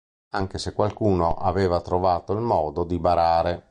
Read more Conj Pron Adv Frequency A1 Pronounced as (IPA) /se/ Etymology From Latin sī (“if”) or from Late Latin se(d), from Latin sī and quid ("what").